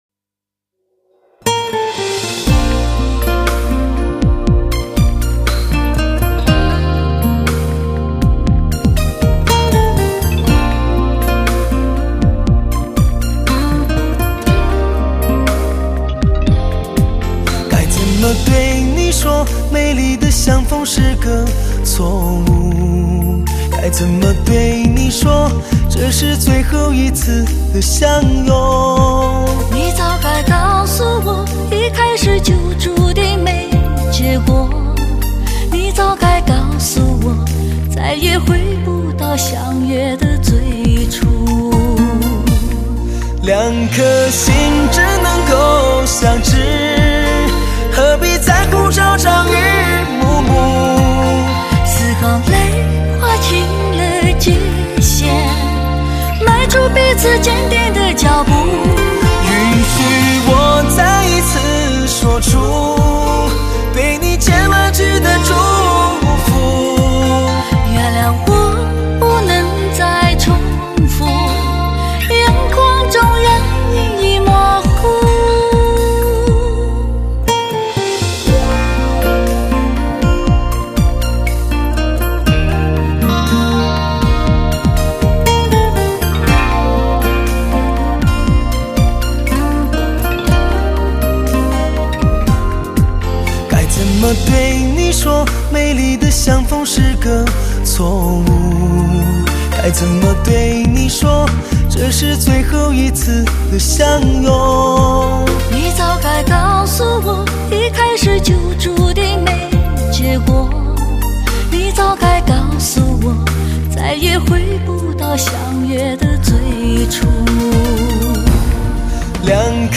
唱片类型：华语流行
新专辑延续其一贯的风格，以伤感爱情歌曲为主打，深情磁性的声线，表达出现实的 情感，让你回忆起爱情过程中的点点滴滴。